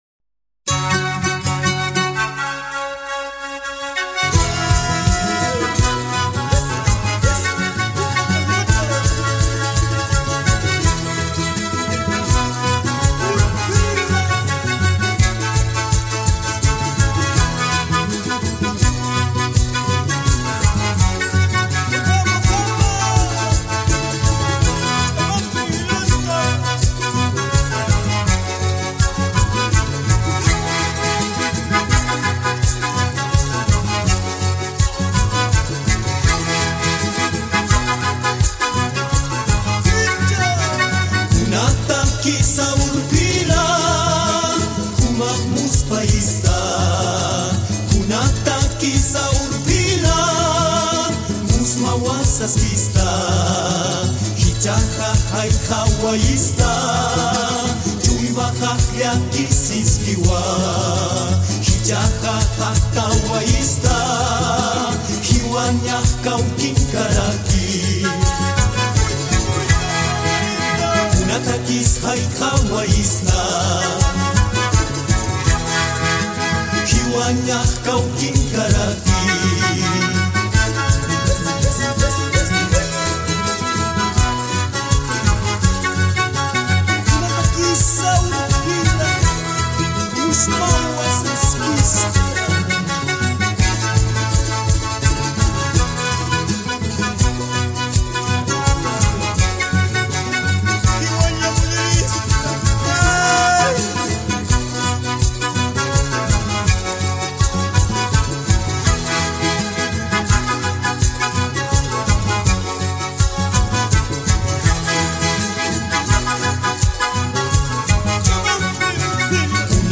Trough the sounds of panpipes